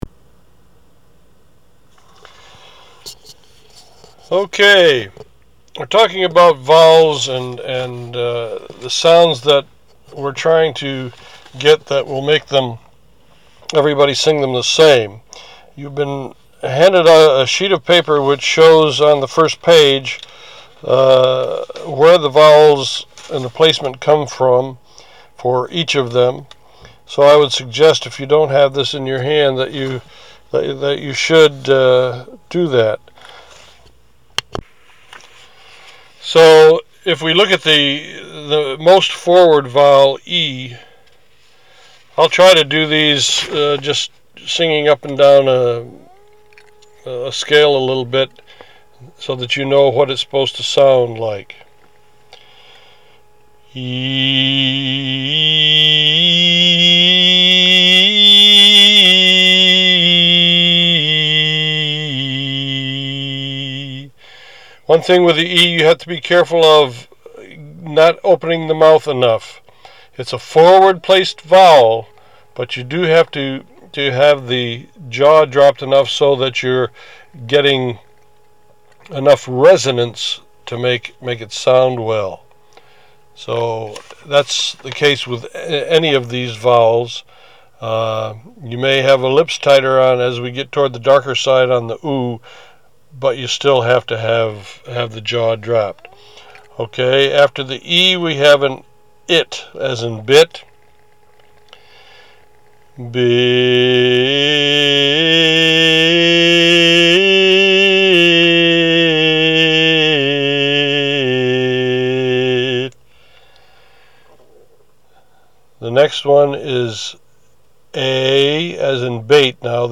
Our chorus deals with 12 pure vowel sounds.  Listen to our director explain and demonstrate the way to form these vowels on
Craft.Vowel-Sounds-Track-1.mp3